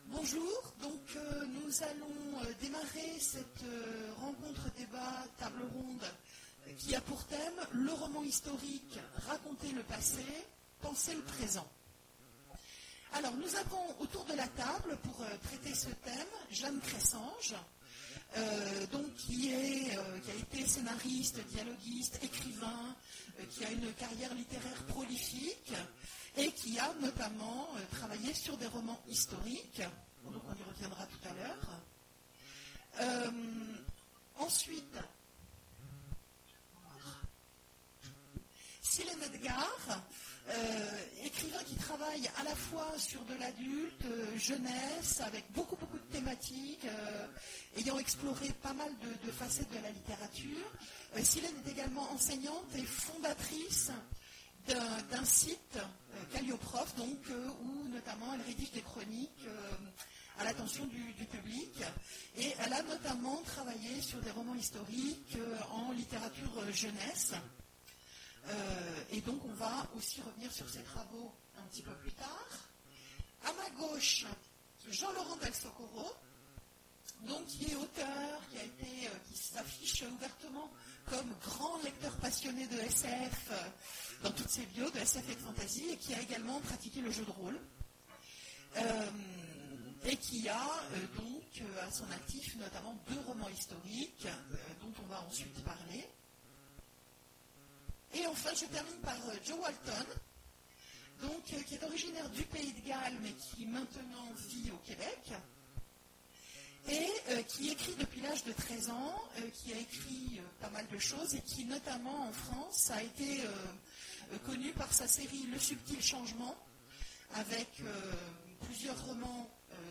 Mots-clés Historique Conférence Partager cet article